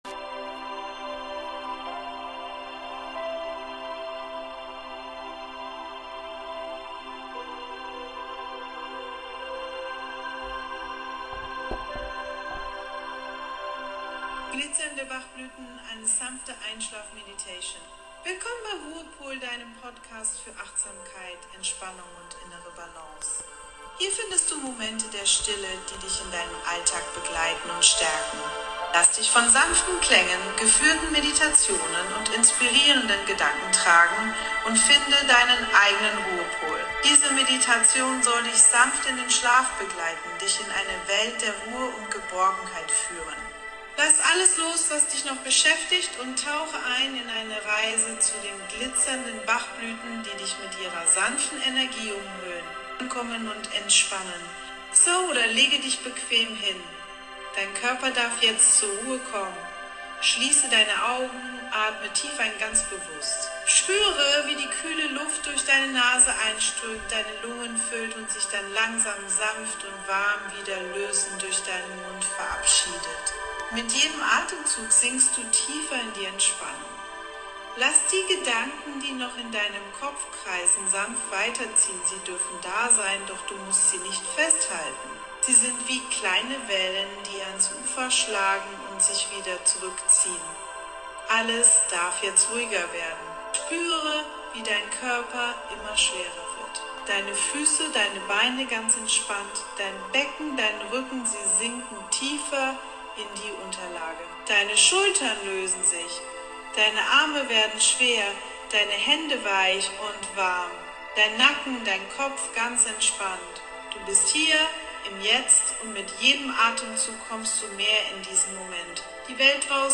Ruhepol – Gute Nacht Glitzerblüten Meditation Sanft eintauchen in